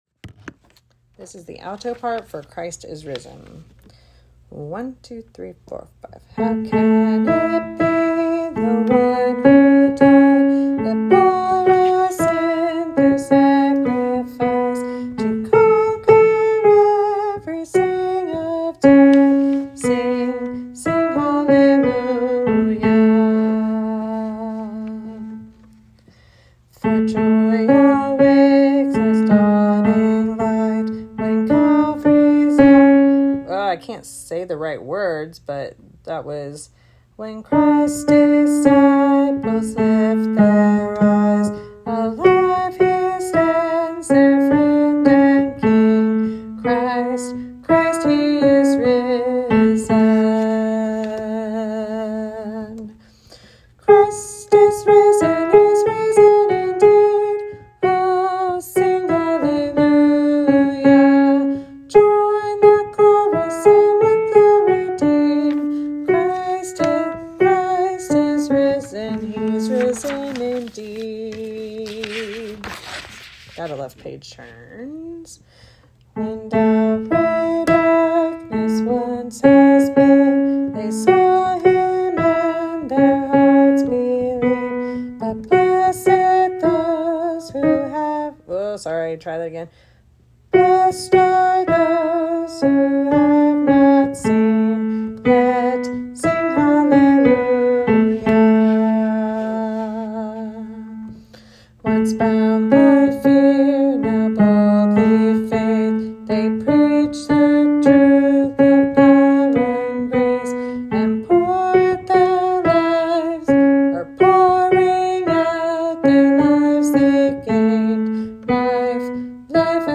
Alto Parts for Easter